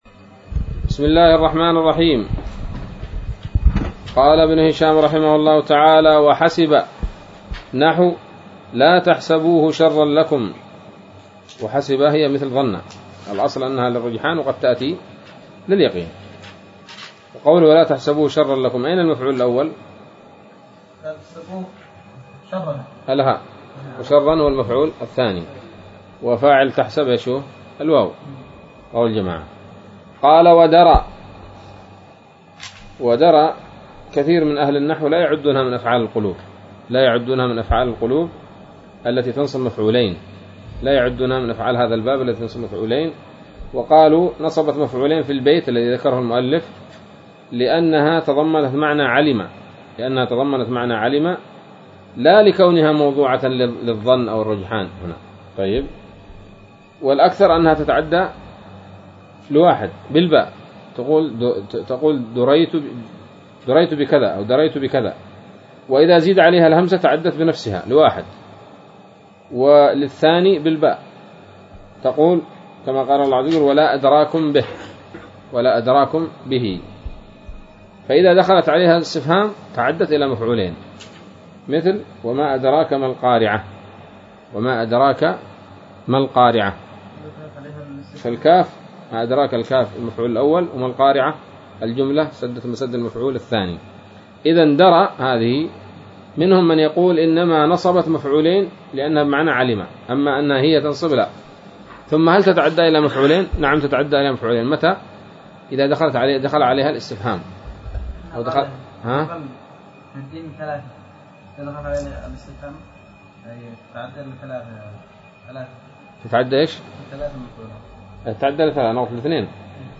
الدرس السبعون من شرح قطر الندى وبل الصدى